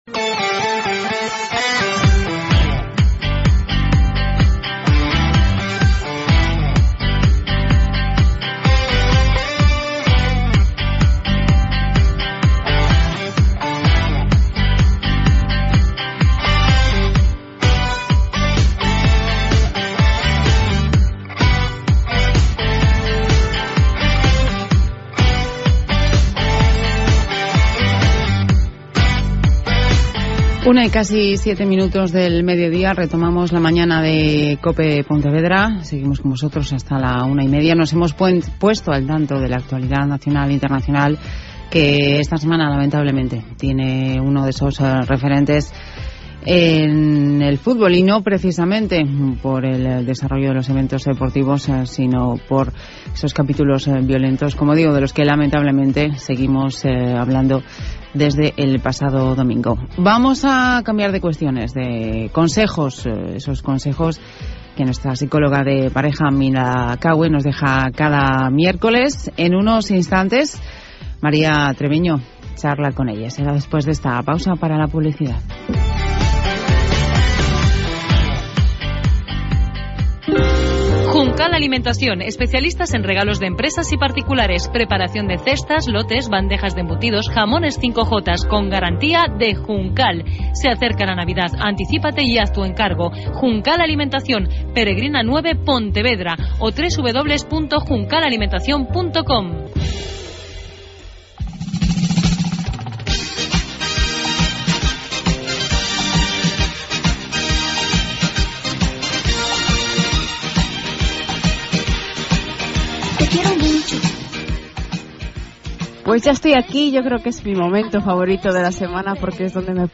Radio: Podcast Programa «La Mañana» de COPE Pontevedra.
Mi intervención abarca desde el minuto 2:00 hasta el minuto 10:35